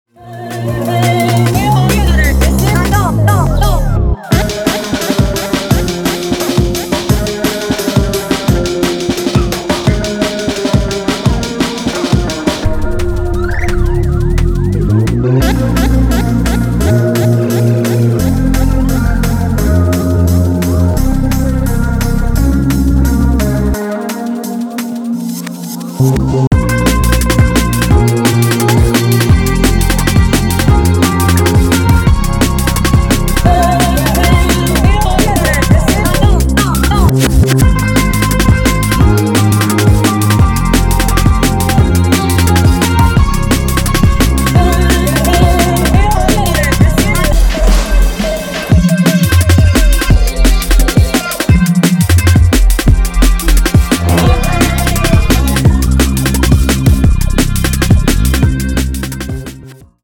イラン伝統楽器サントゥールの魅惑的な音色を用いたエクスペリメンタルなダブワイズ作品